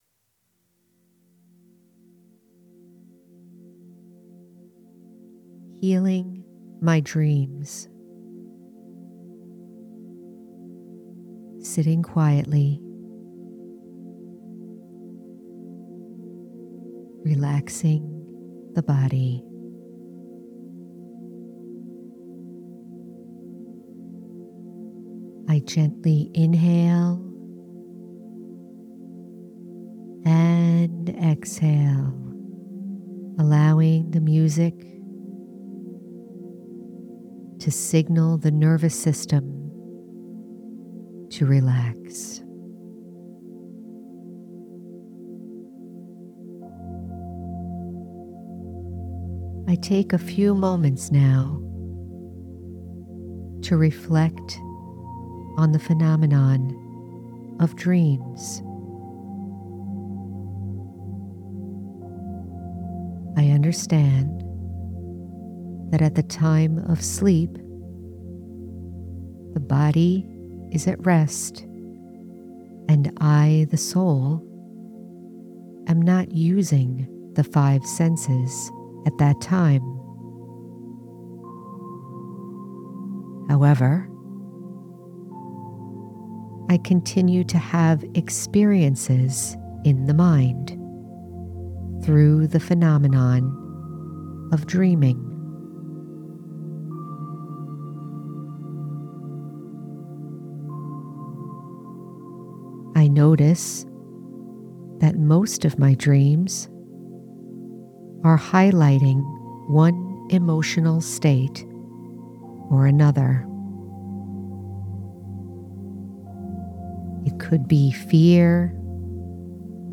Healing my Dreams- Guided Meditation- The Spiritual American- Episode 170